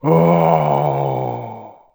c_zombim1_atk3.wav